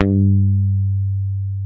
-JP PICK G 3.wav